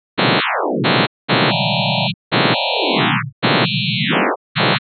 Transforming an image into sound with Coagula is ridiculously simple, just click the ‘gears icon’ to process the image and select ‘Save Sound As…’ from the ‘File’ menu.